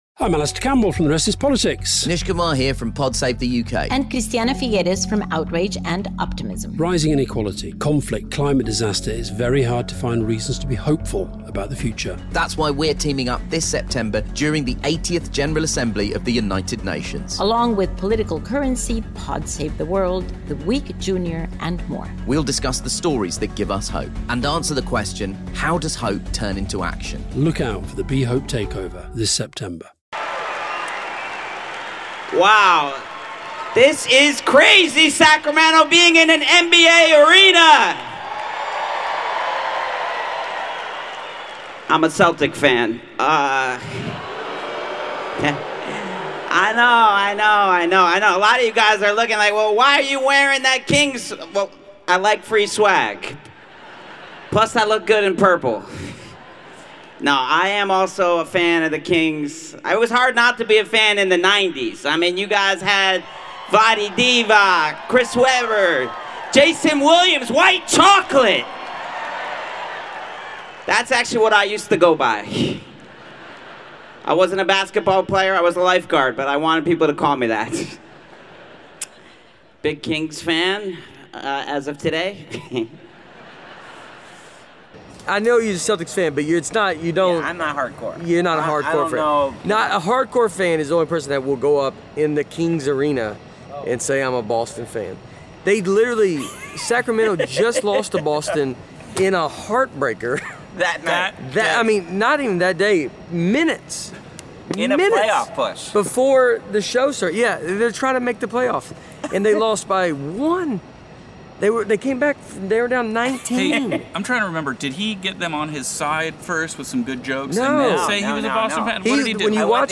This show is recorded while on the road with Nate Bargatze.
This episode was recorded on the Sacramento, Stateline and Anaheim stops of the be funny tour.